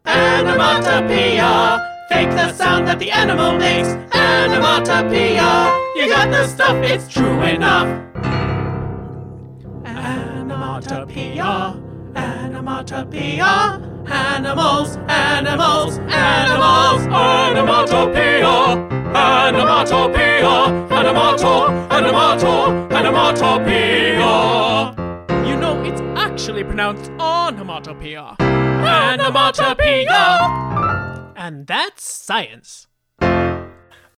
RUTH – Lower Harmony (SWING 2)
SAM – Upper Harmony, Tenor octave (SWING 1)